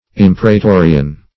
Imperatorian \Im*per`a*to"ri*an\, a.